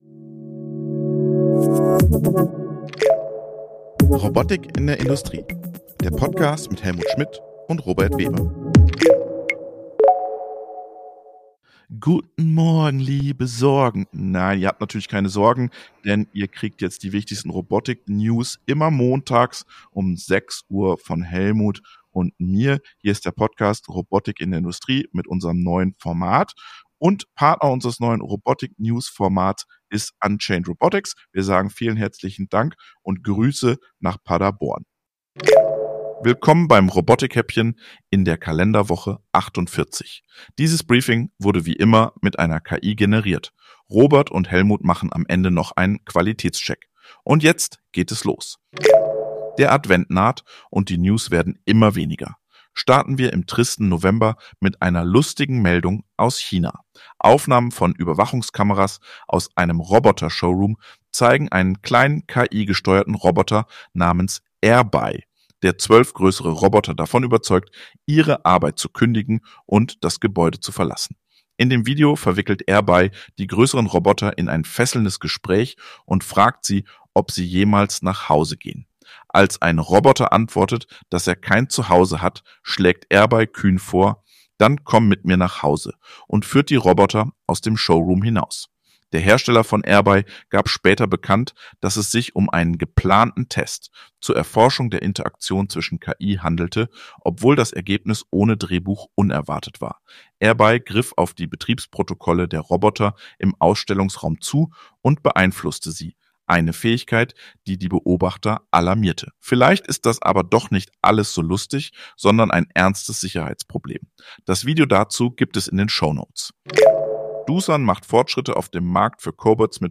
Unser Newshäppchen für die Fahrt zur Arbeit. Immer Montags gibt es die Robotik News - mit-recherchiert, geschrieben und gesprochen mit und von einer KI.